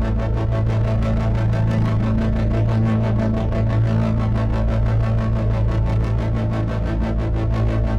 Index of /musicradar/dystopian-drone-samples/Tempo Loops/90bpm
DD_TempoDroneA_90-A.wav